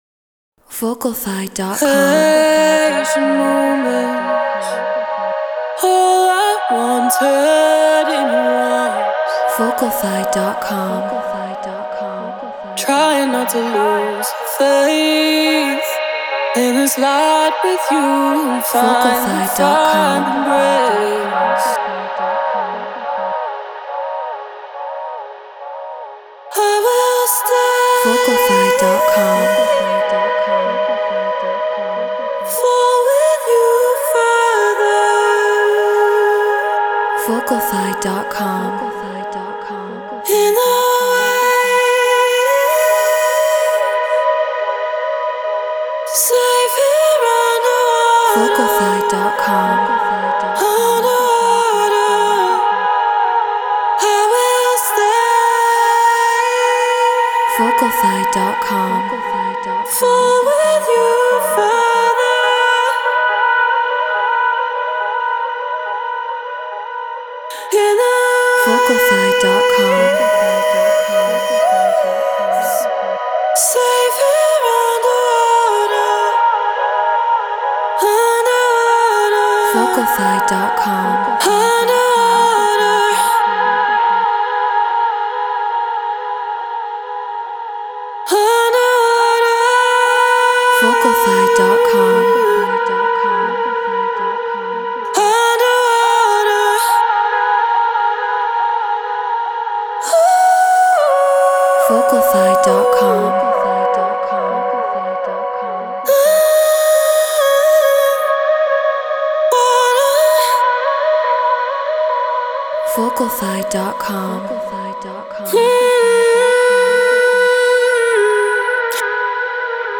Trance 140 BPM Bmaj
Human-Made